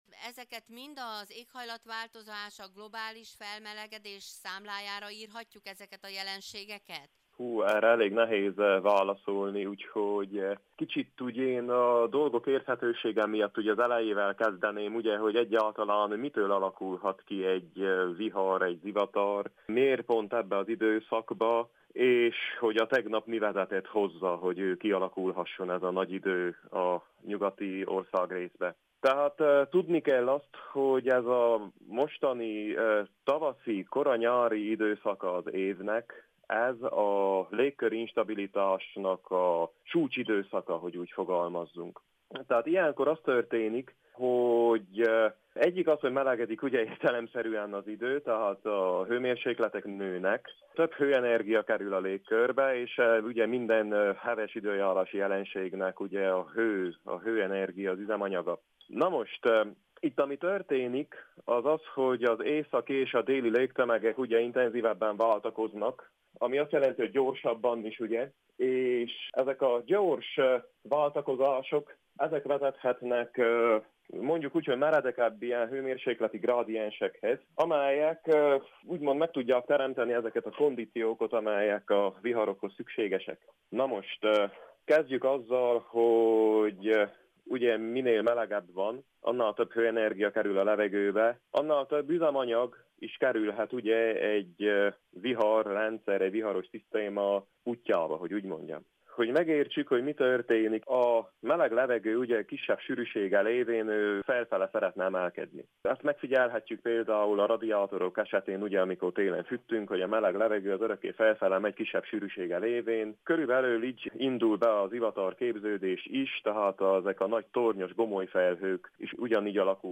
telefonon, hogy értelmezze ezeket a szélsőséges időjárási jelenségeket.